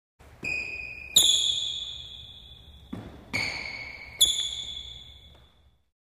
Sală de sport, Fluierând din pantofi în interior | efect sonor .mp3 | Descarca gratis.
Fluierând din pantofi în interior, sală de sport: